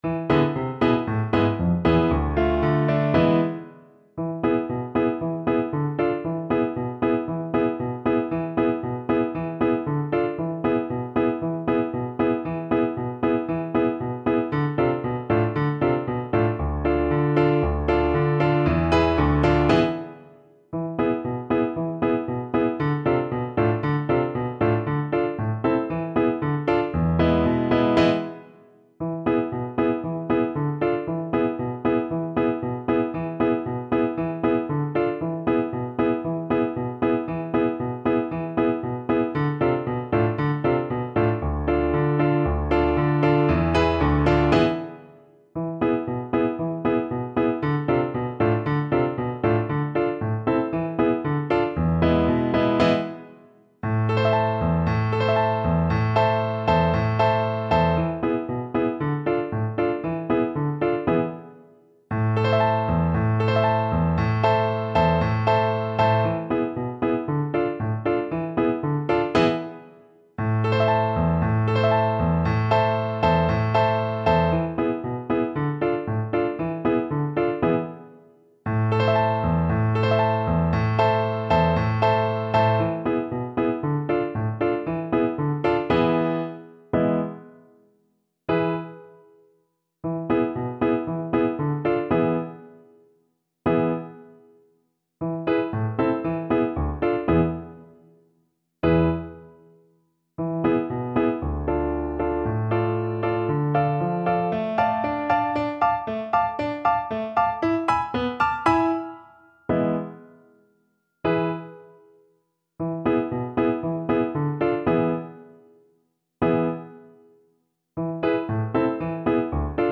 Play (or use space bar on your keyboard) Pause Music Playalong - Piano Accompaniment Playalong Band Accompaniment not yet available reset tempo print settings full screen
A minor (Sounding Pitch) E minor (French Horn in F) (View more A minor Music for French Horn )
Allegro =c.116 (View more music marked Allegro)
2/4 (View more 2/4 Music)
Traditional (View more Traditional French Horn Music)